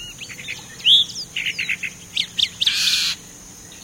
Mimus triurus - Calandria real
calandriareal.wav